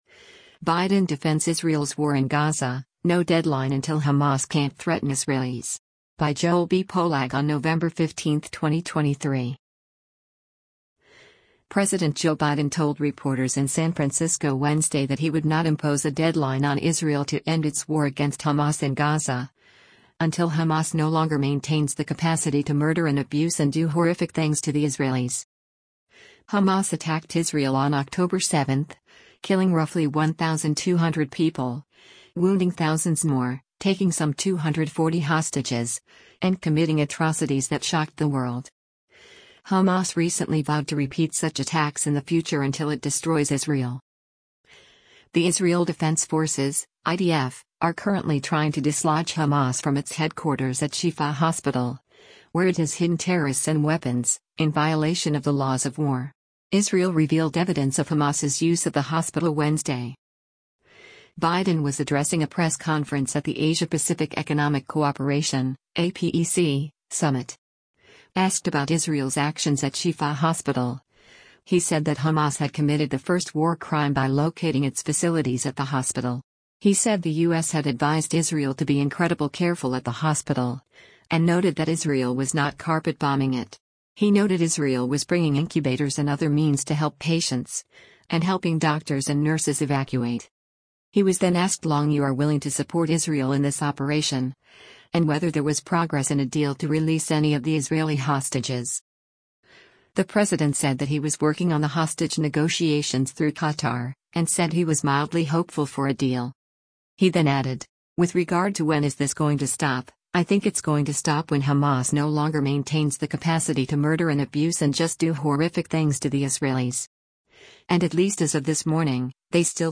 Biden was addressing a press conference at the Asia-Pacific Economic Cooperation (APEC) summit.